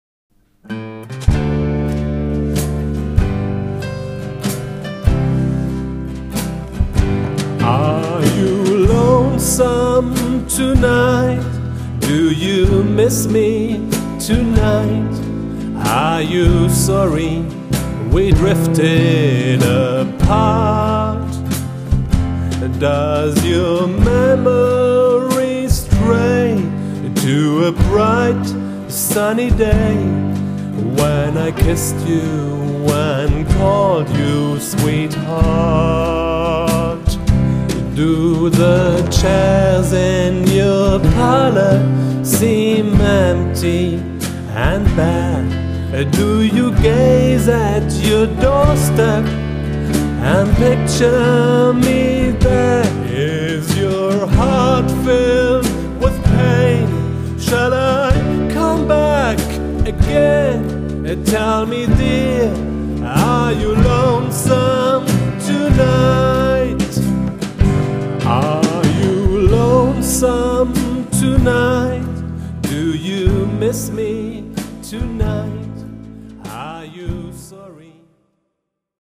Keyboard
Schlagzeug
Gitarre